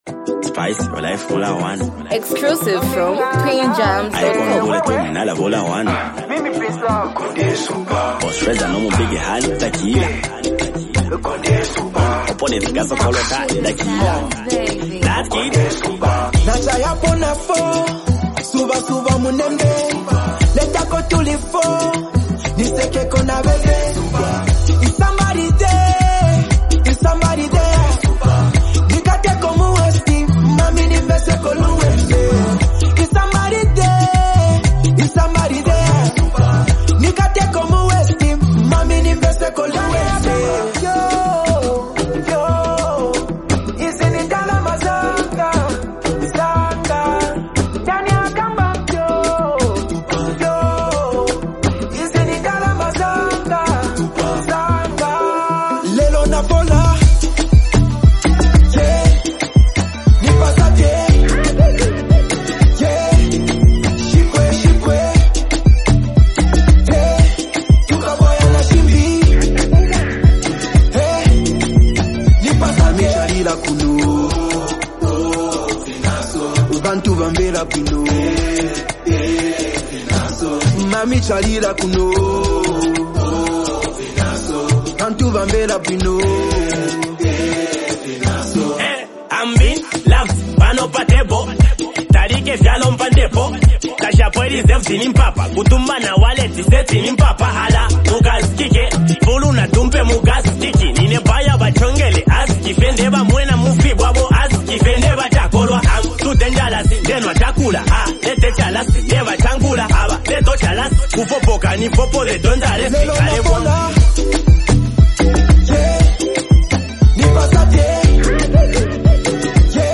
smooth vocals
catchy chorus, rhythmic bounce